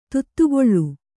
♪ tuttugoḷḷu